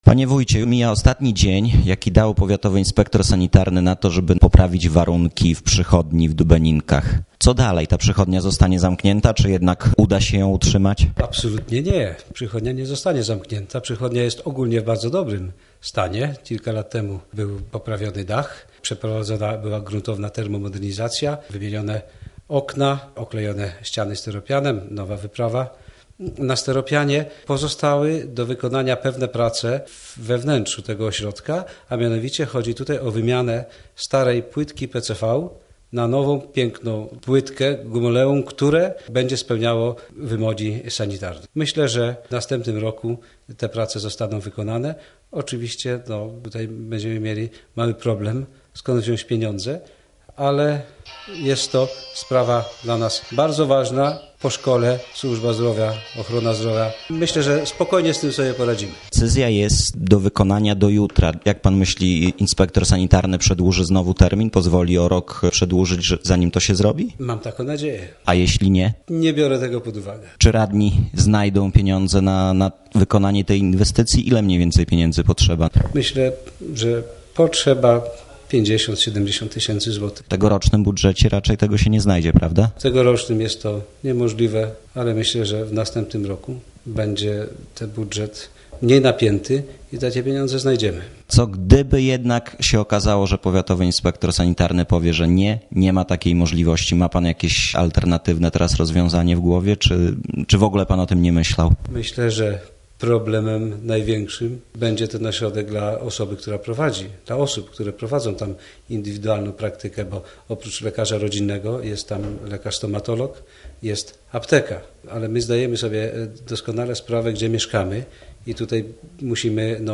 rozmowa z wójtem Ryszardem Zielińskim